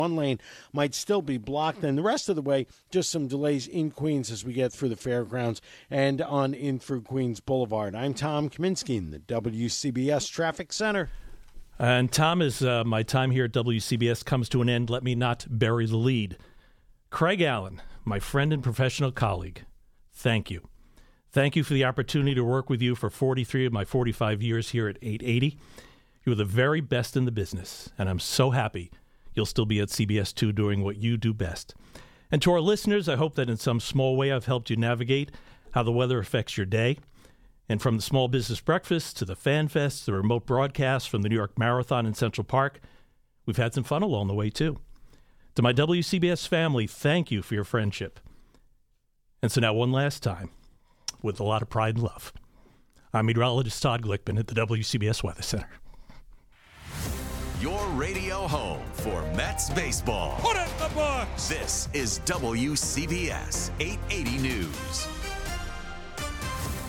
Last weathercast goodbye, Monday, 19Aug24 at 9:28am